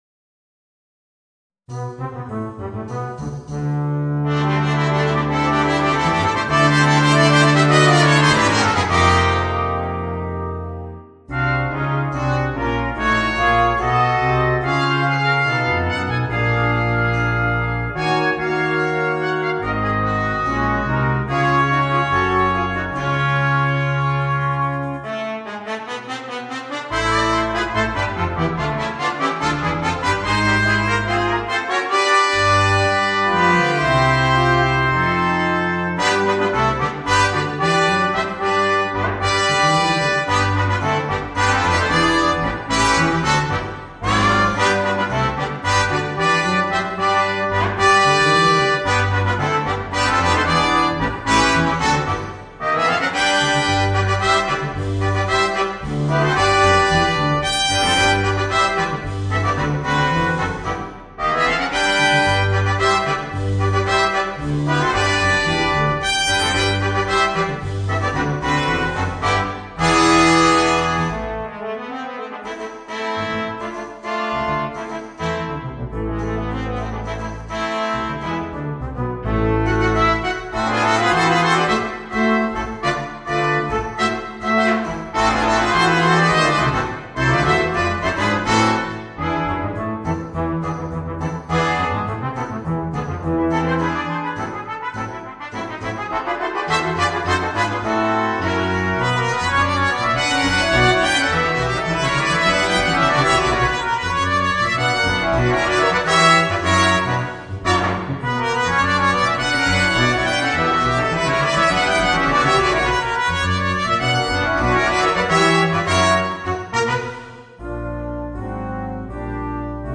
Voicing: 5 - Part Ensemble